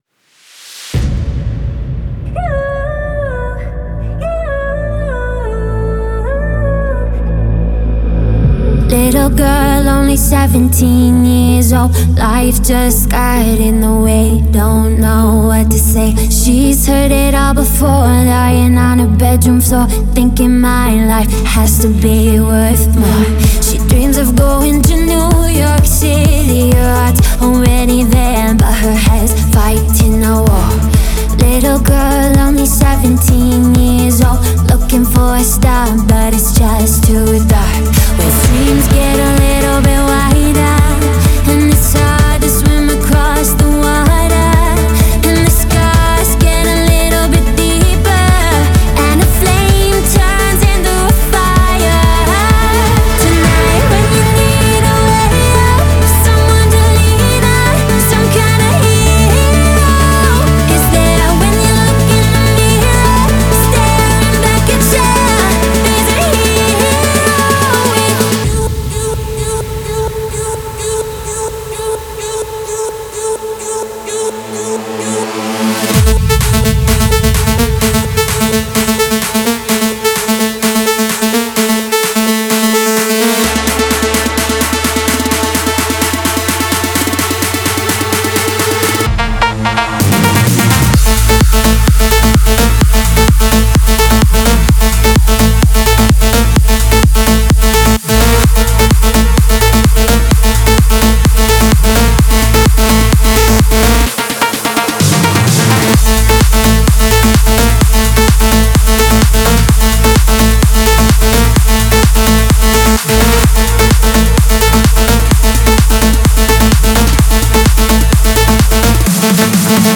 мощная EDM-композиция